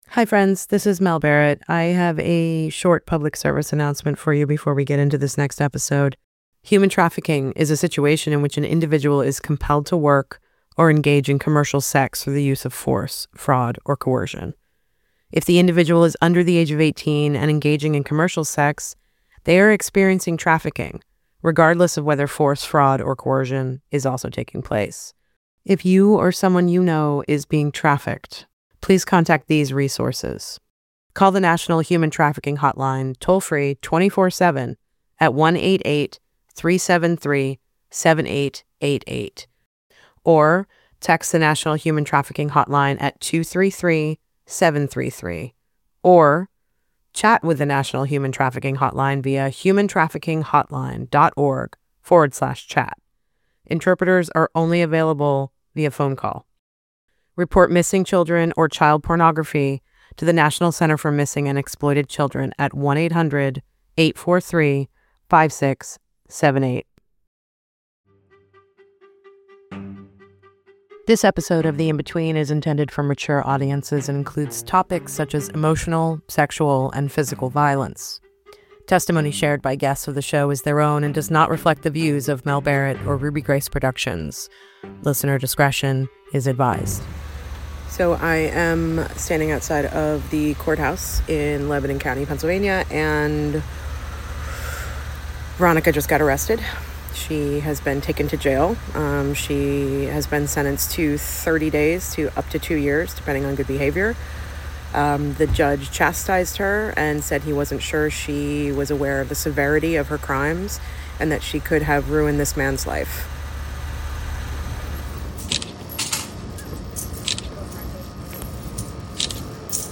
This is an in-depth discussion of what happened then and what's to come with fellow podcaster